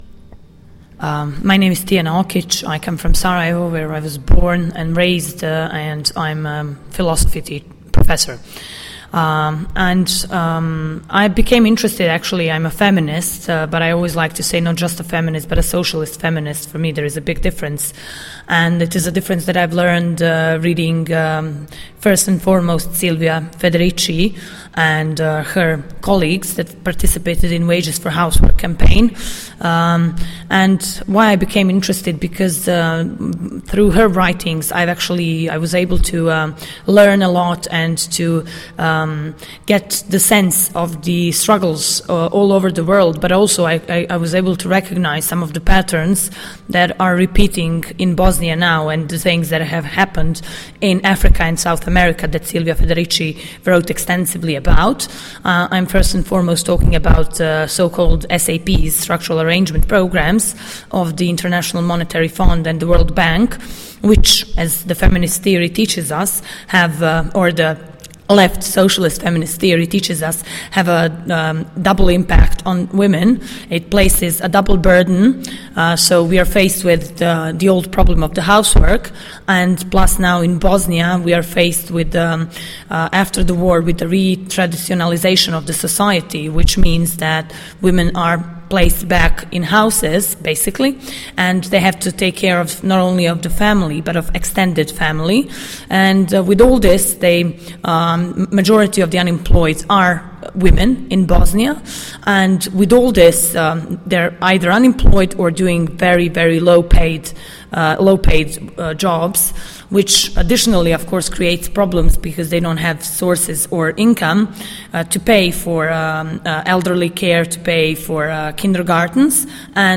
Radio WIBG interview
From the CADTM Europe Summer University: The second day offered many workshops to continue the exploration of “The debt in all its state” and moreover the resistance that is being organized around the world.